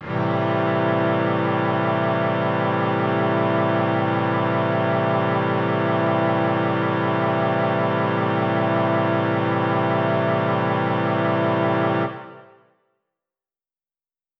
SO_KTron-Cello-Amin7.wav